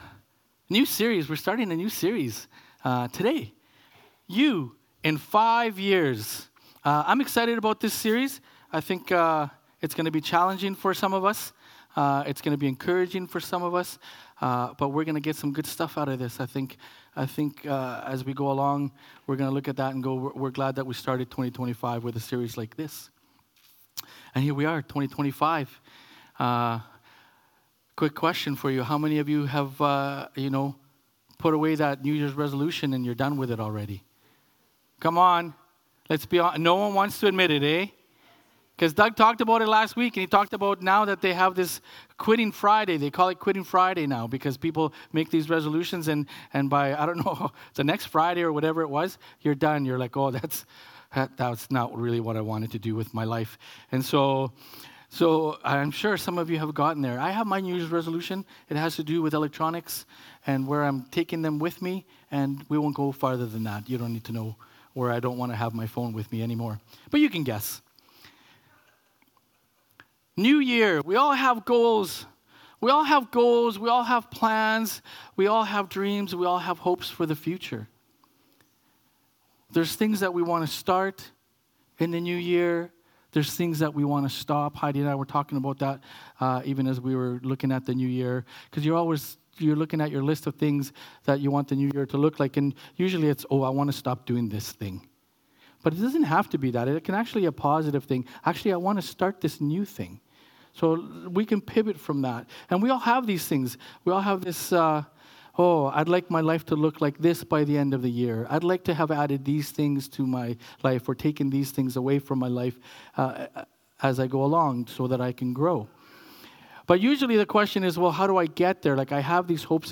Sermons | City Light Church